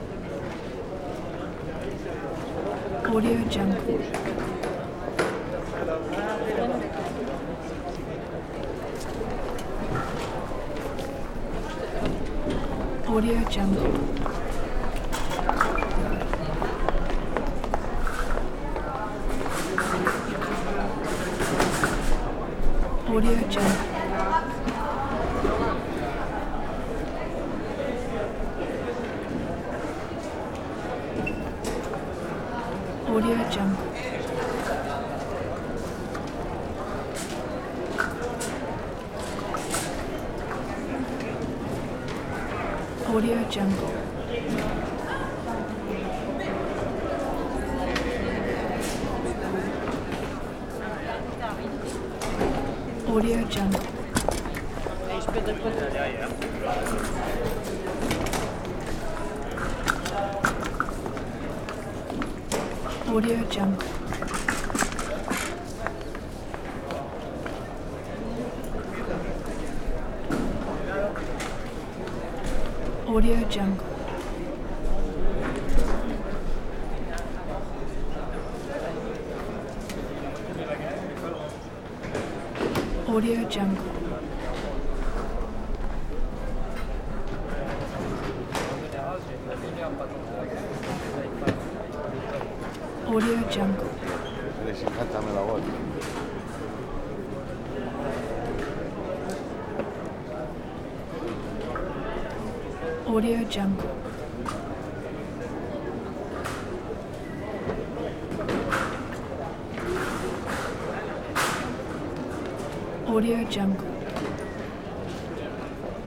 دانلود افکت صدای ترمینال فرودگاه پاریس
دانلود افکت صوتی شهری
Sample rate 16-Bit Stereo, 44.1 kHz
Looped No